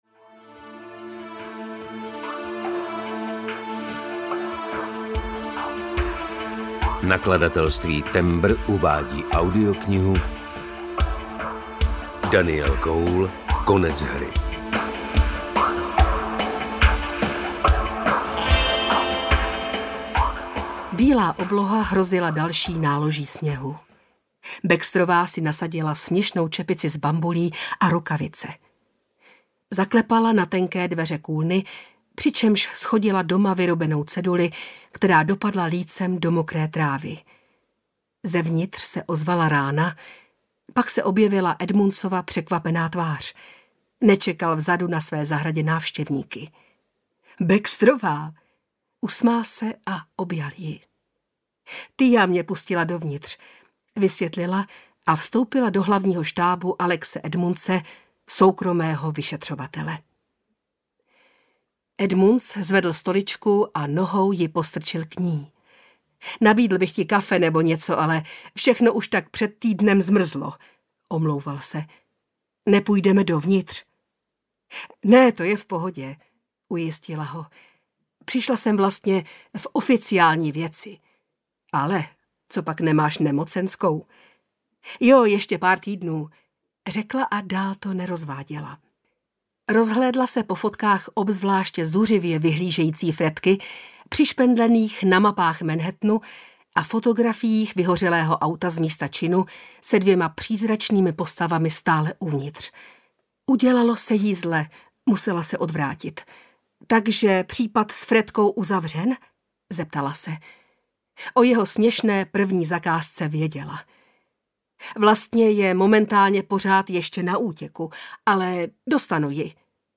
Konec hry - 2. vydání audiokniha
Ukázka z knihy